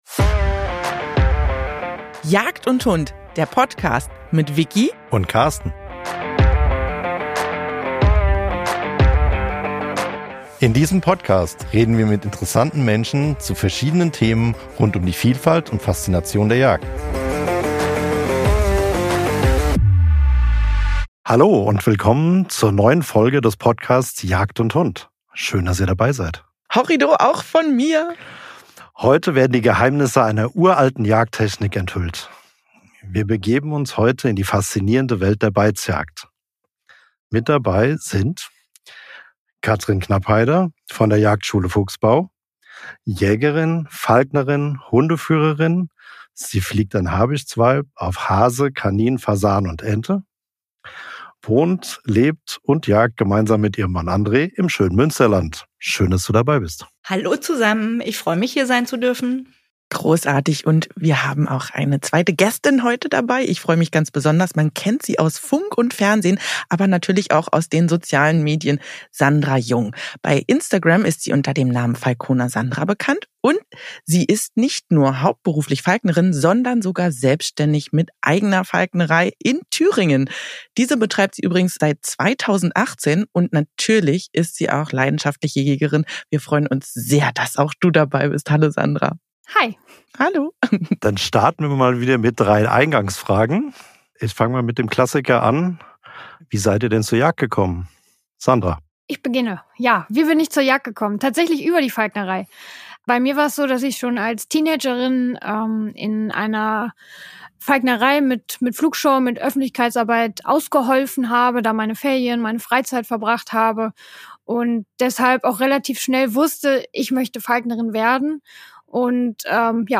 Für einen regen Austausch haben sie die beiden Falknerinnen